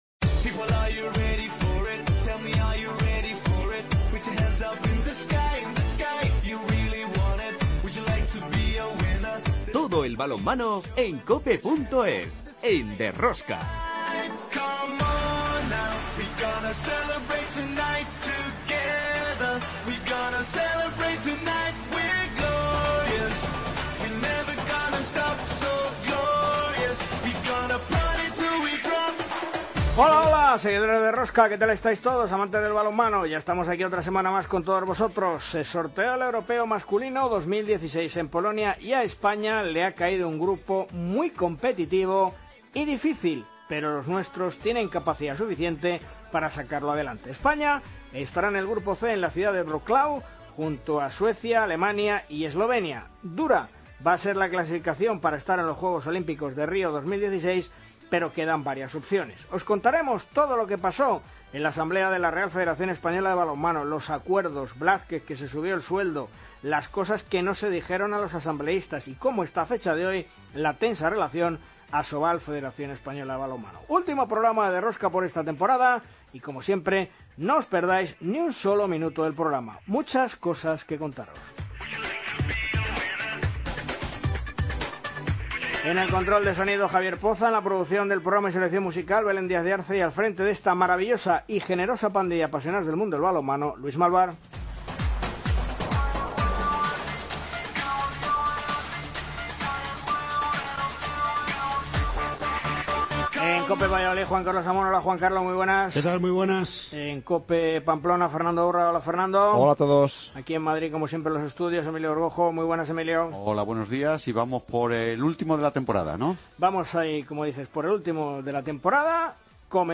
Entrevistas a Manolo Cadenas